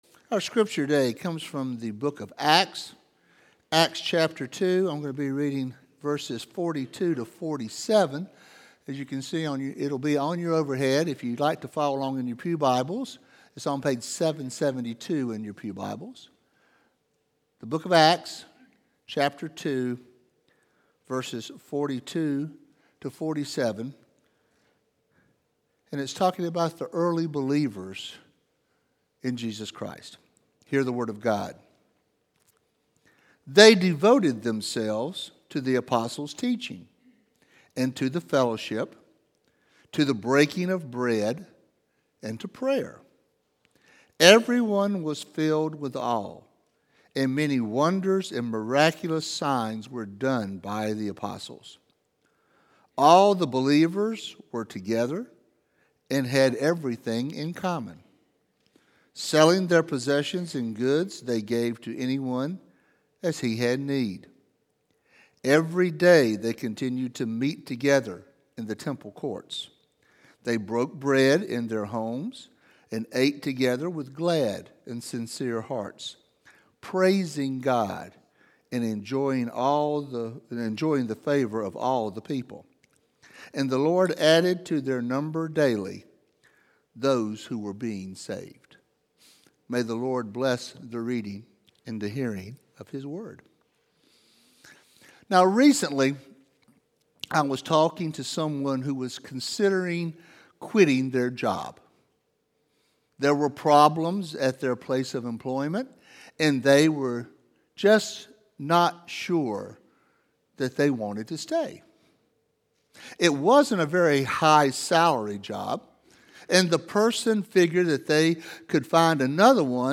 East Naples United Methodist Church Sermons